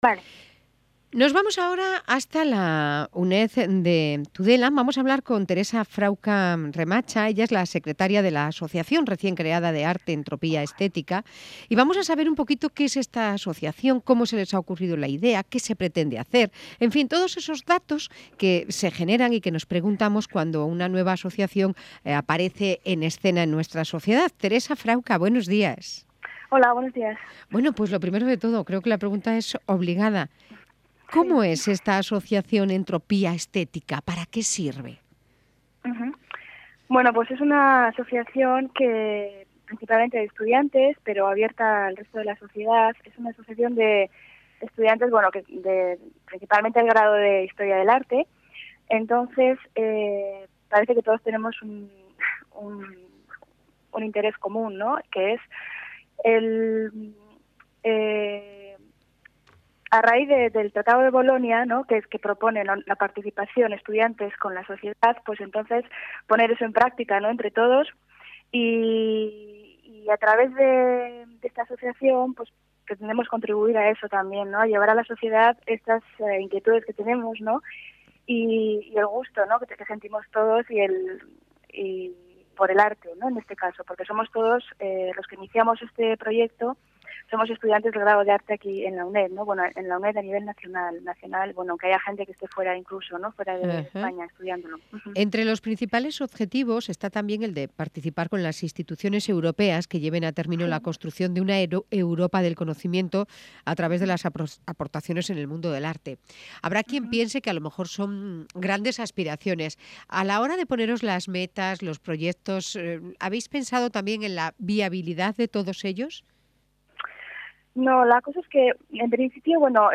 Cadena Ser (4-2-2011) UNED 4 FEB AS ENTROPIA